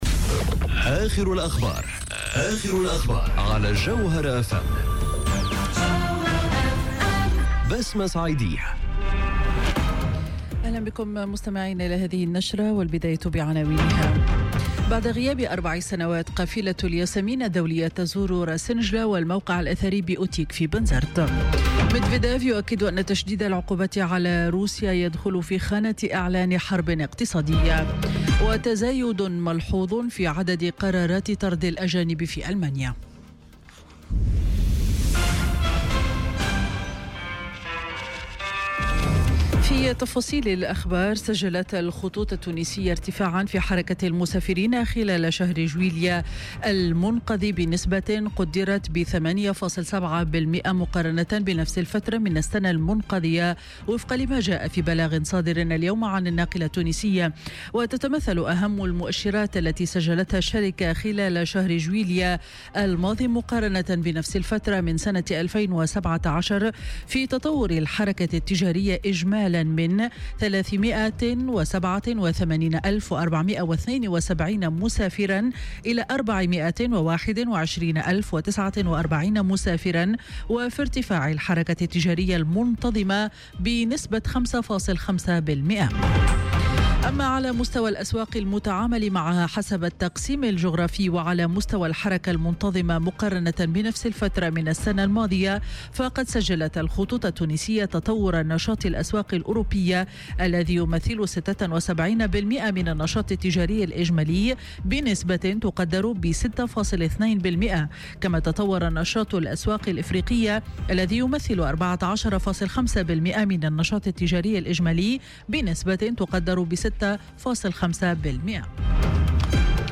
نشرة أخبار منتصف النهار ليوم الجمعة 10 أوت 2018